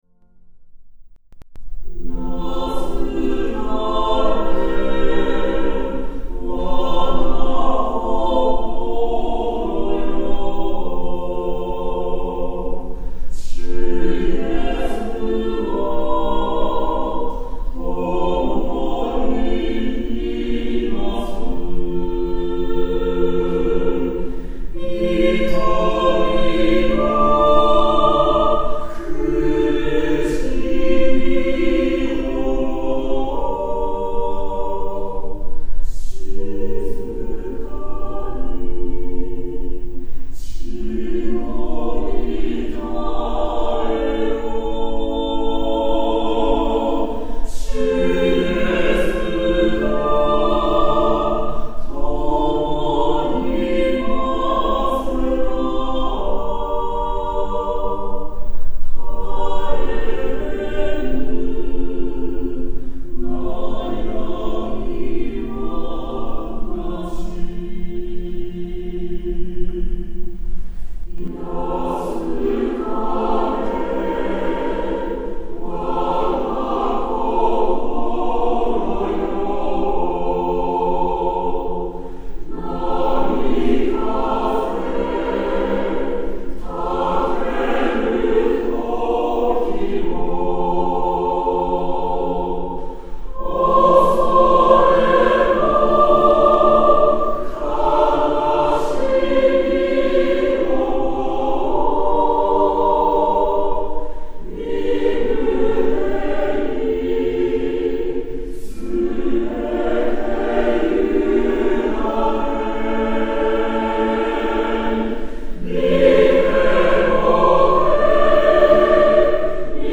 ♪聖歌隊練習参考音源
Tonality = F
Temperament =Equal
演奏例：アカペラ重唱〜アカペラ合唱〜合唱とオルガン